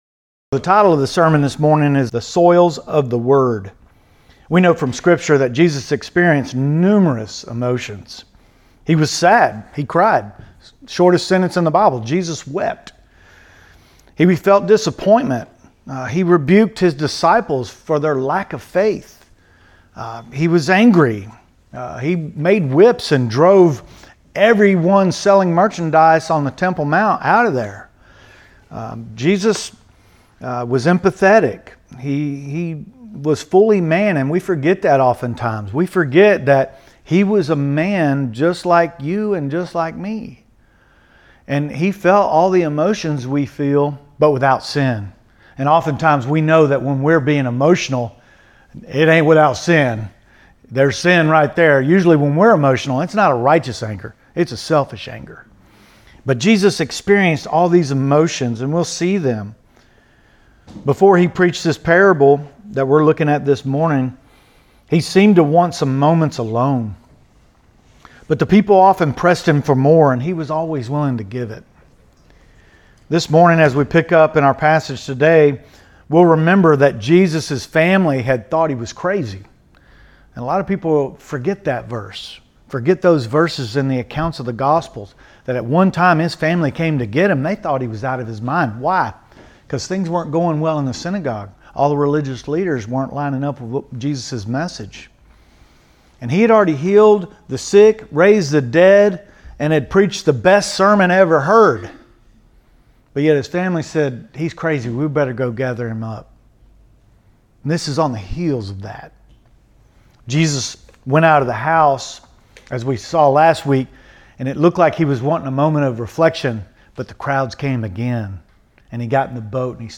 Sermons from Monte 2024 Recordings, Resources & Notes